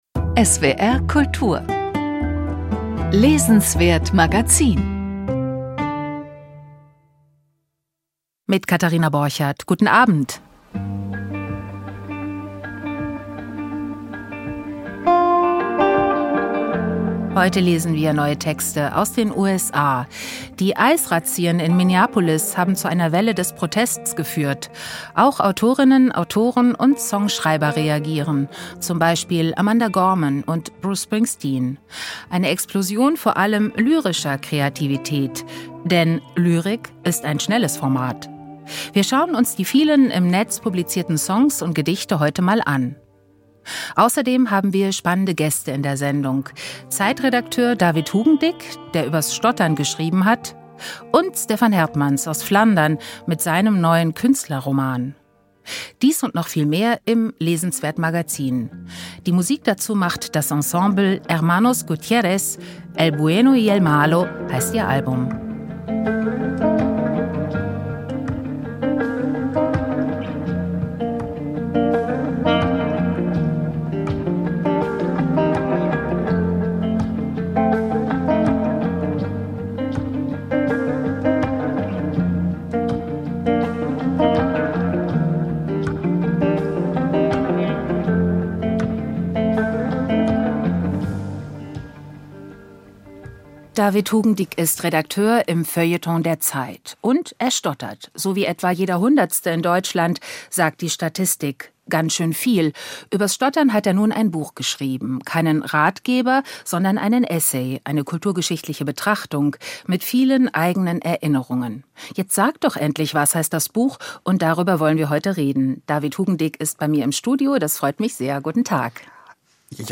Heute sprechen wir über das Stottern. Und hören neue Songs und Lyrik über Minneapolis. Wir begegnen „Alten Meistern“ bei Heinrich Steinfest und im neuen Roman von Stefan Hertmans.